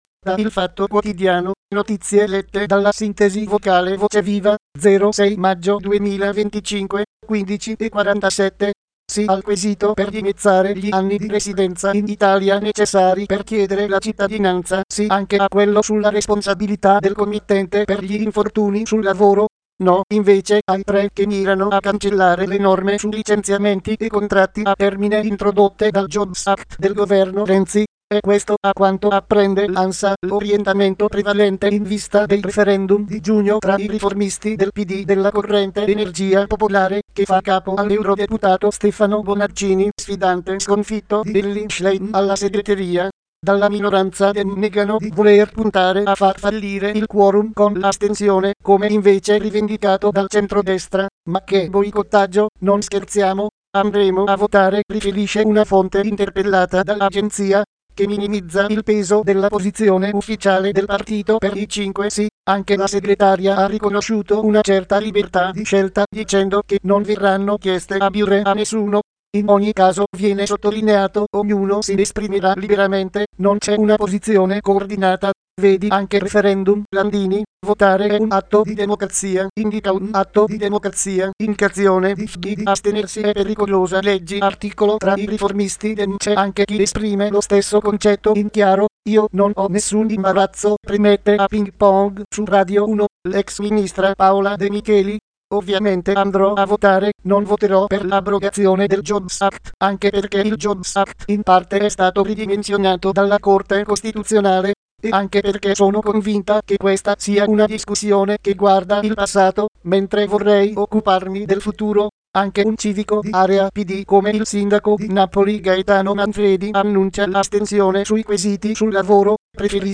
Nuova generazione di sintesi vocale
Software di sintesi vocale TTS - Text To Speech